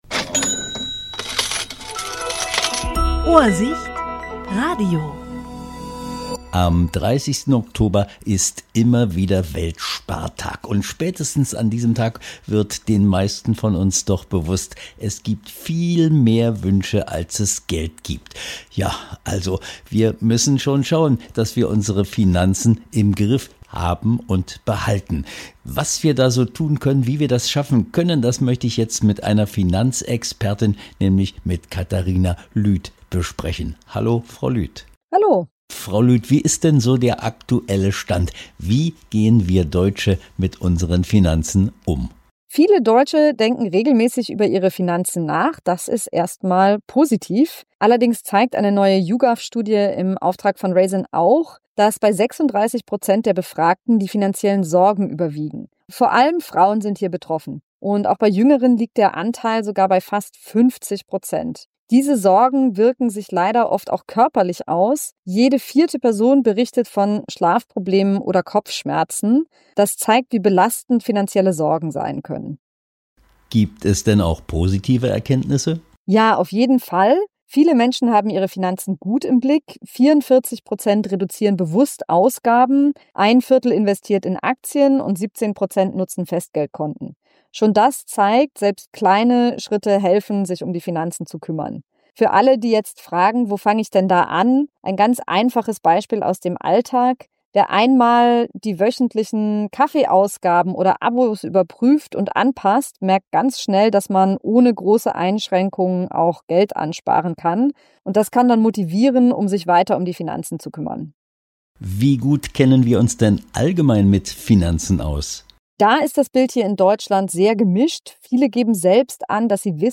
Interview 30.10.2025